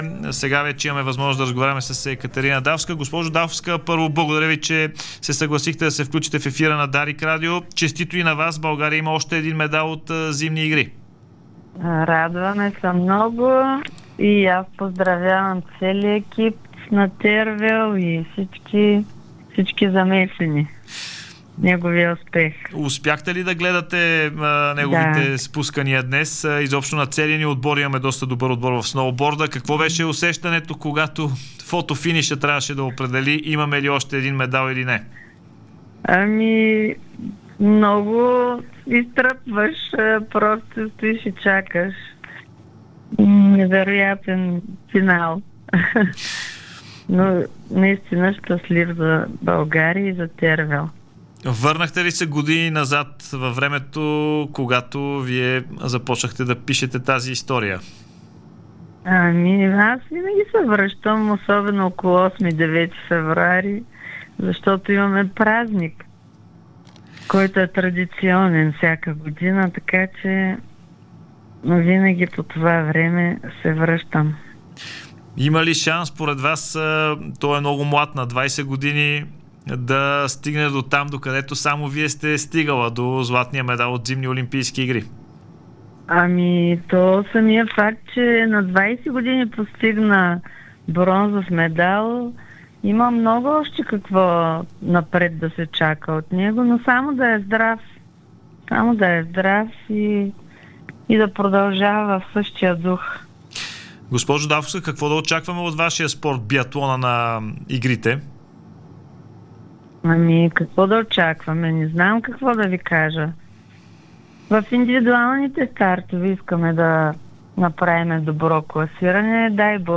Единствената златна медалистка от Зимни Олимпийски игри Екатерина Дафовска даде специално интервю в ефира на Дарик радио броени минути след бронзовия медал на Тервел Замфиров в сноуборда.